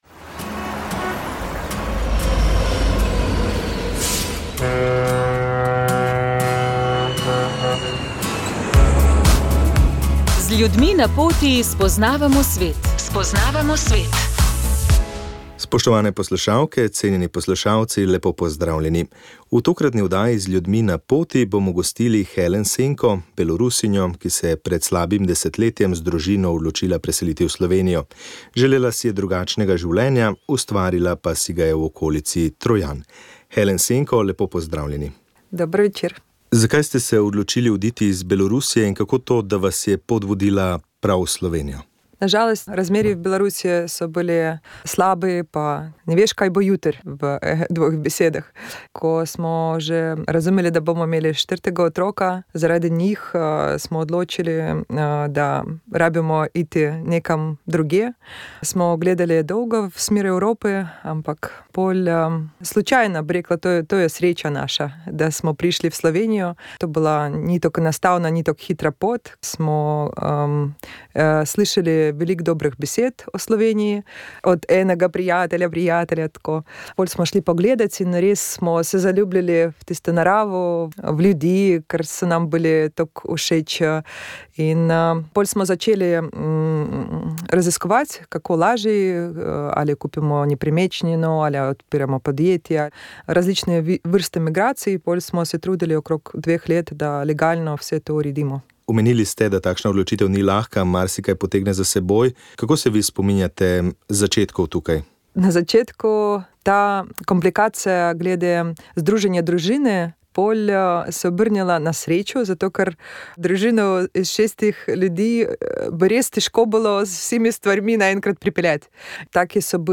Za naš radio je spregovorila o življenju v tujini ter o tem, kako vidi Združene arabske emirate in tamkajšnje prebivalce.